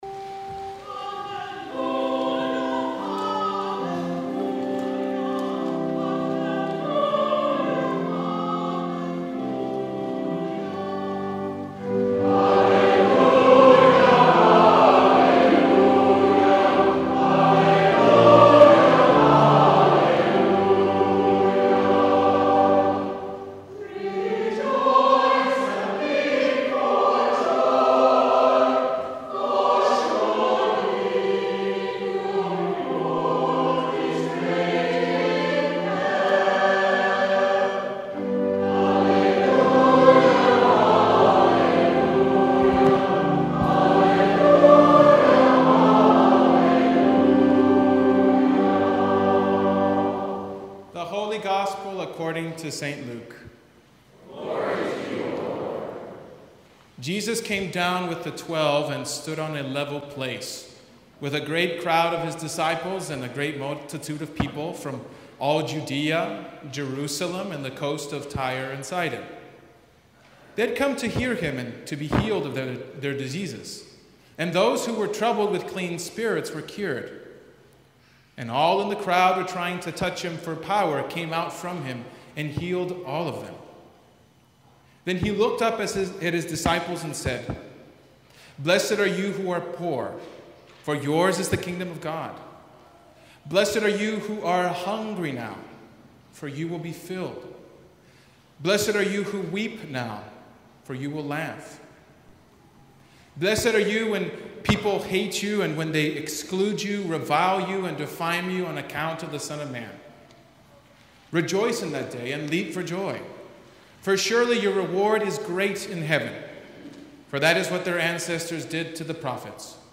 Sermon from the Sixth Sunday After Epiphany